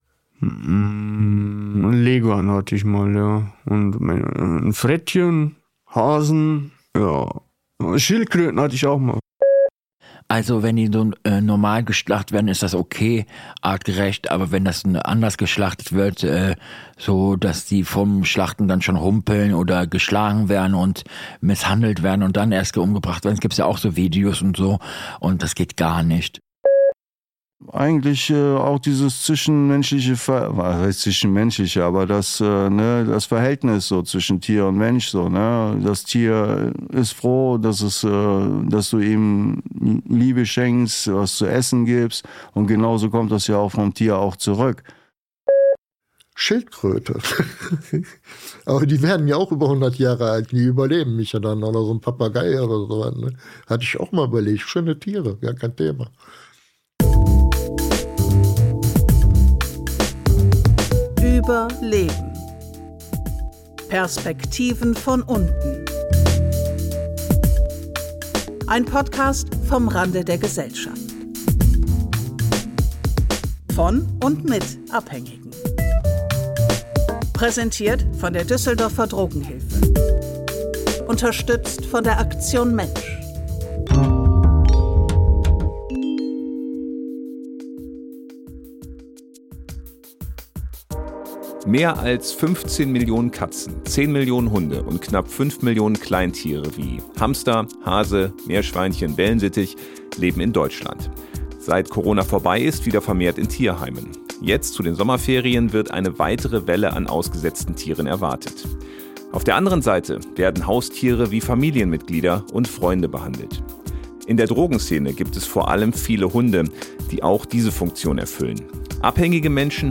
In dieser Folgen erzählen Euch Abhängige über ihre Haustiere, über Hunde von Obdachlosen und Verantwortung gegenüber Tieren. Außerdem reden sie über Tiere als Lebensmittel.